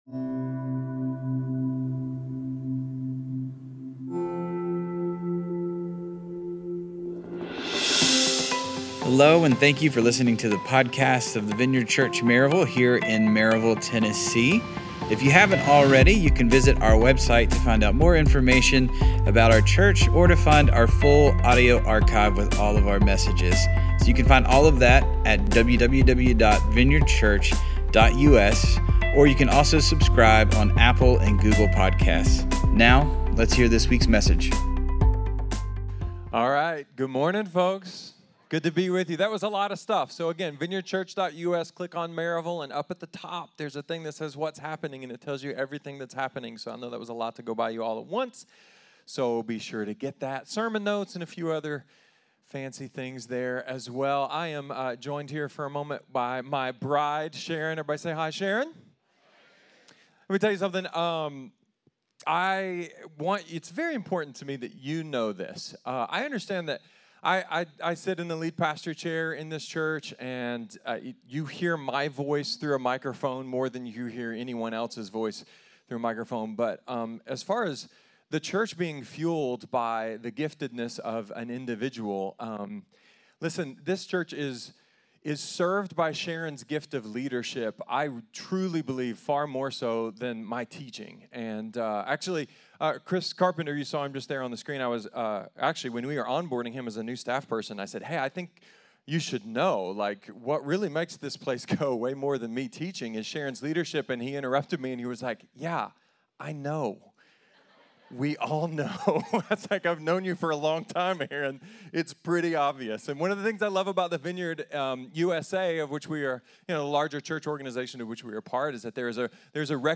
A sermon about how we think of God, how God thinks of us, and finally moving past ‘sin-management’ and into friendship with Him.